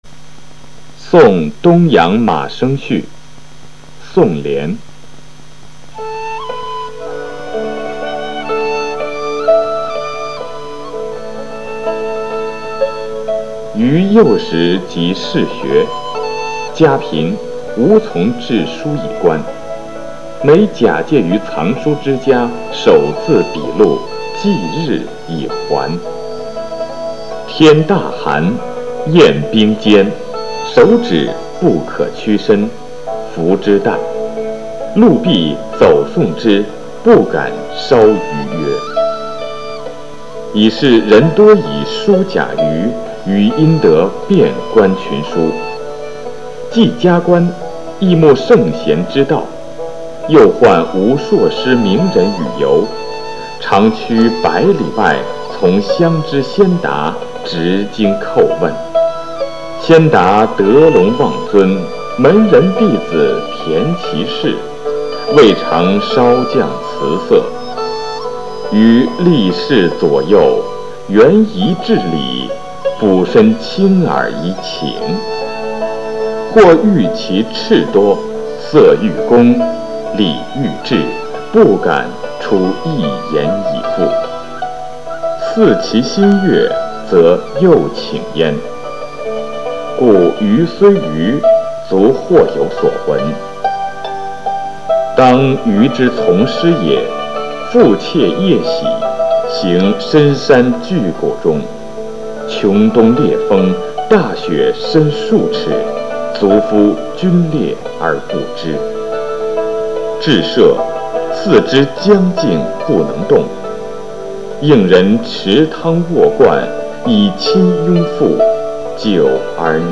《送东阳马生序》配乐朗读